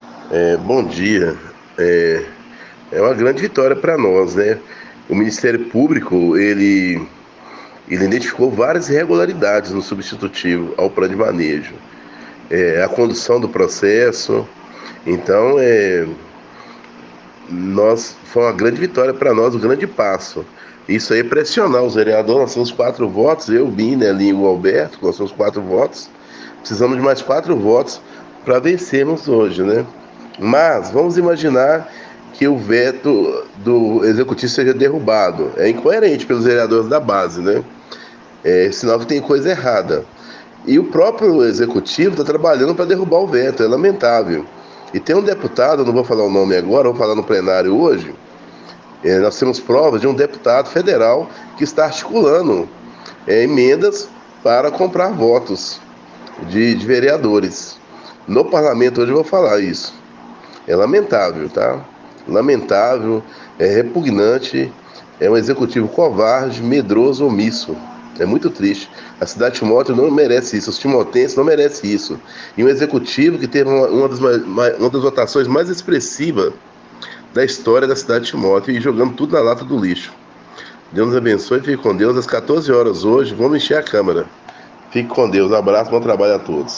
Da tribuna da Câmara, o vereador Adriano Costa Alvarenga revelou que a compra de votos indicada pelo Professor, está direcionada há 10 parlamentares que defendem a derrubada do Veto do prefeito Douglas.